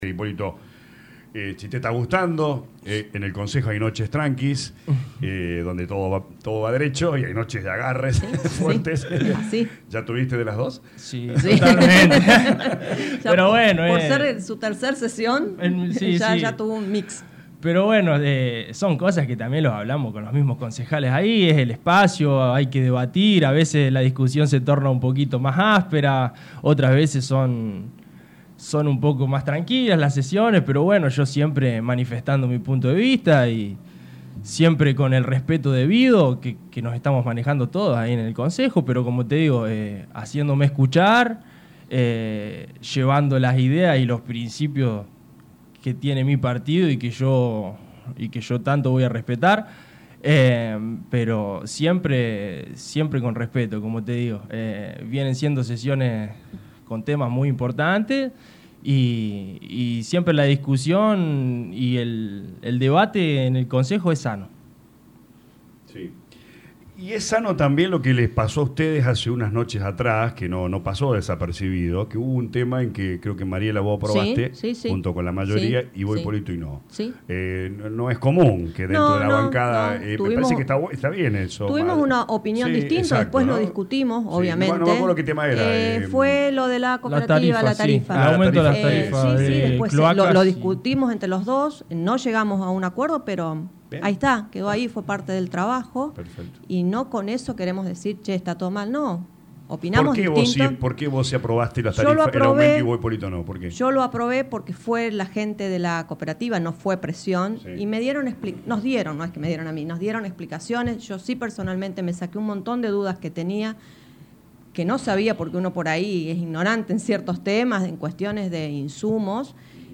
Los concejales Mariela Tavano e Hipólito Tomati de visita a los estudios de LA RADIO 102.9 FM volvieron a reclamar por la falta de respuesta del ejecutivo al pedido de informes.